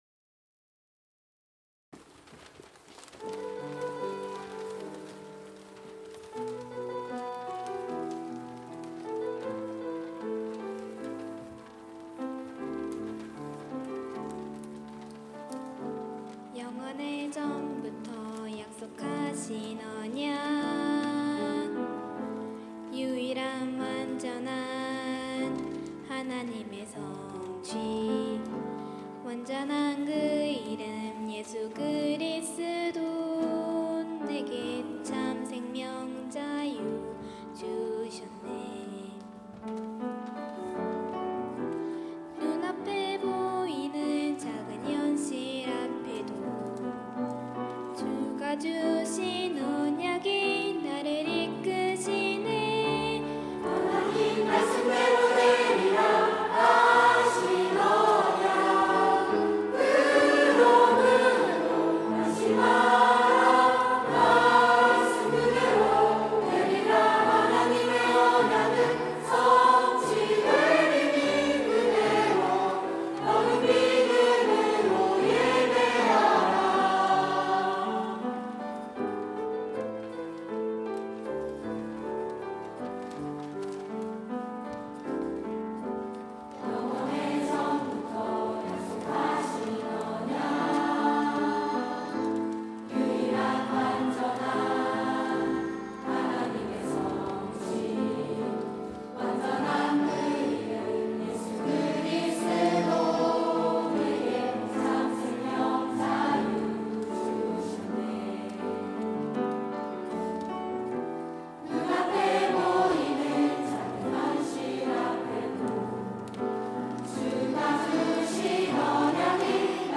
3부 찬양대(~2024)